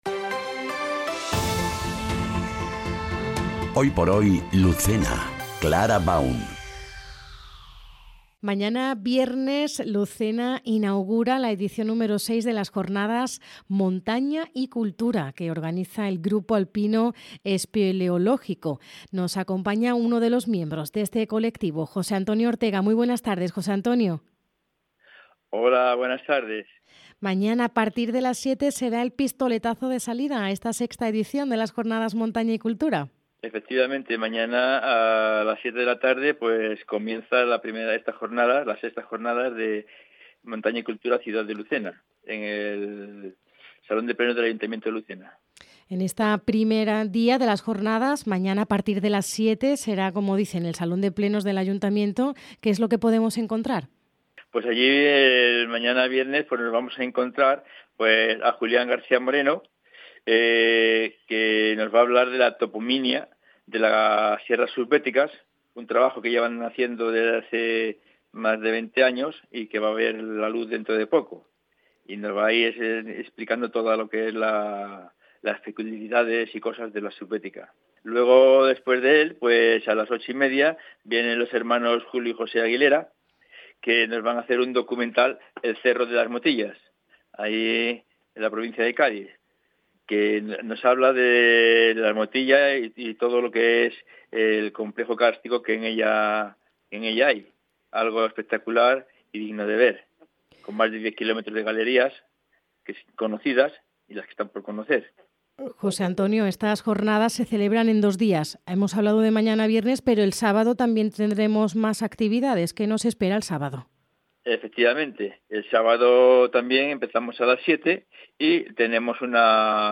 ENTREVISTA | Jornadas Montaña y Cultura Lucena